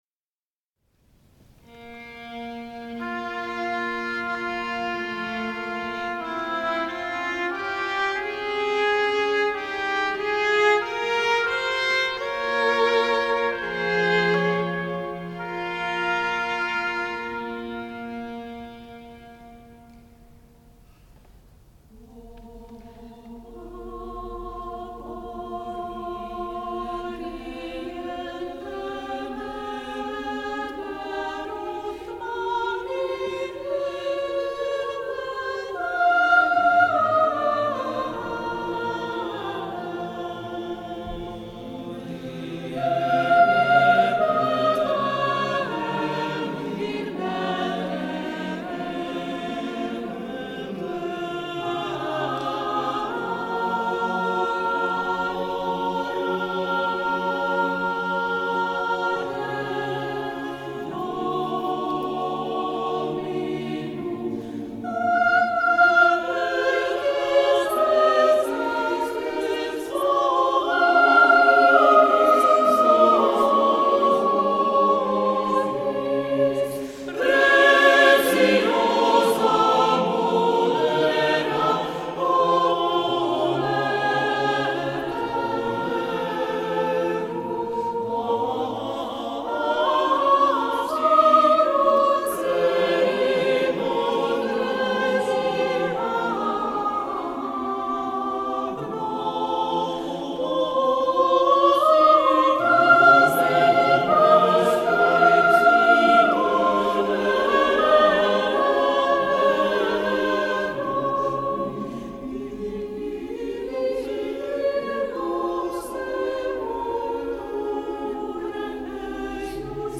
The list origins from concerts performed from 1971.